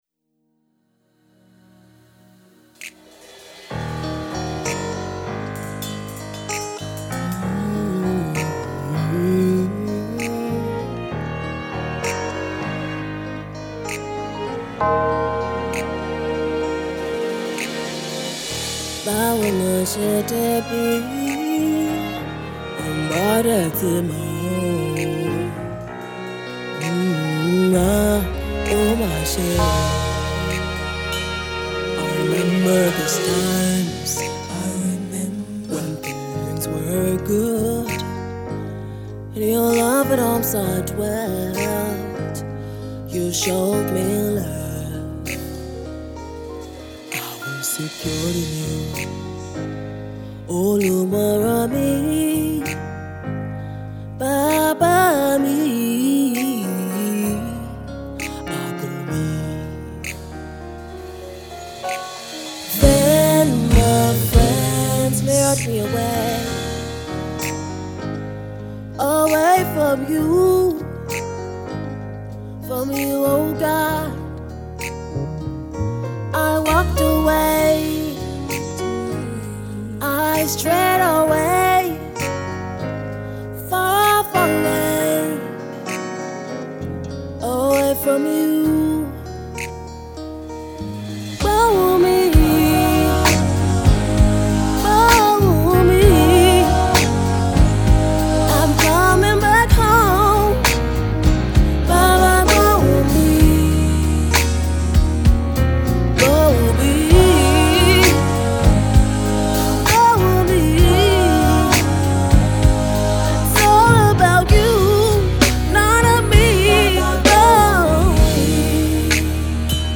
Contemporary African, gospel and inspirational
with the use of contemporary African music genre